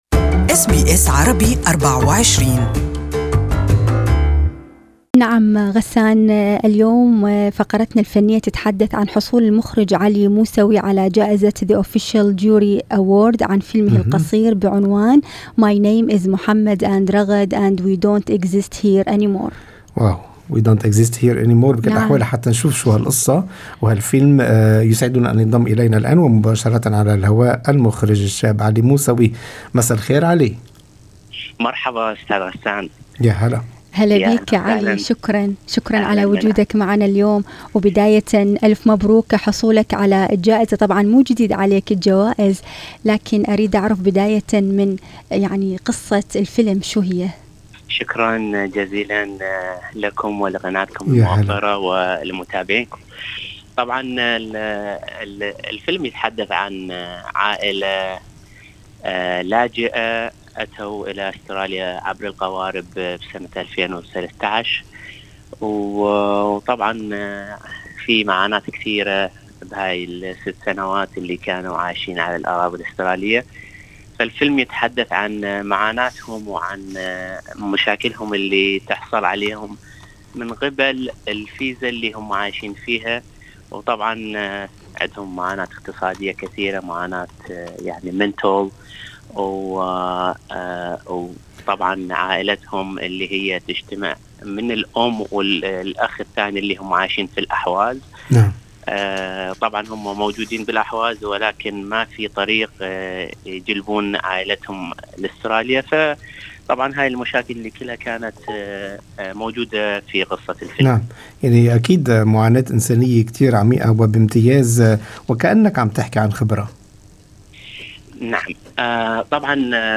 المزيد في لقاءِ مباشر مع المخرج الشاب